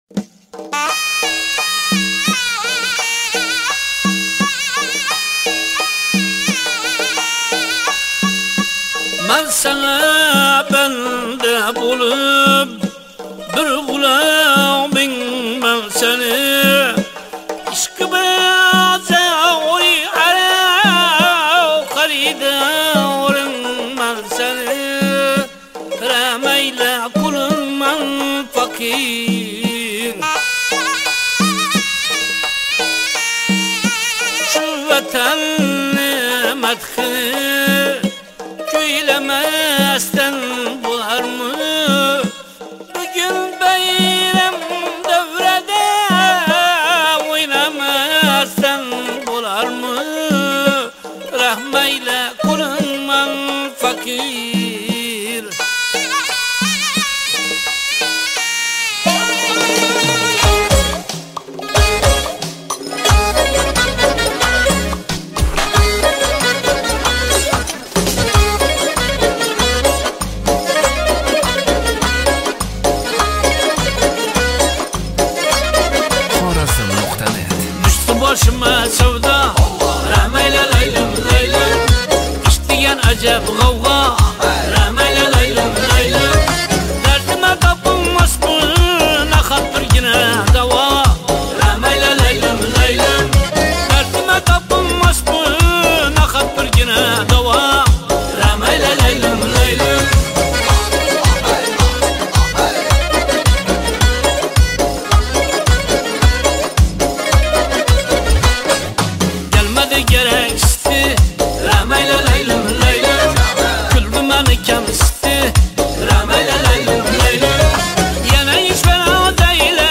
Раҳматжон Қурбонов ижросида